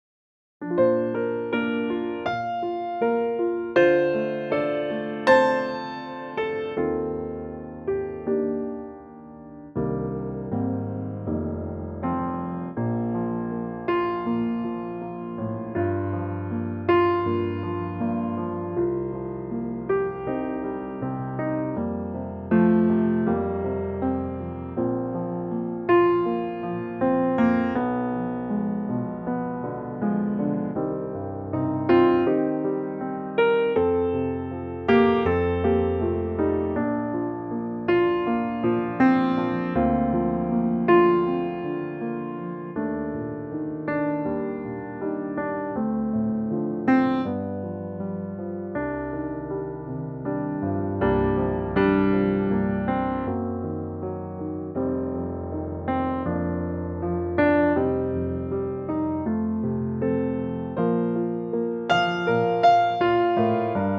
Unique Backing Tracks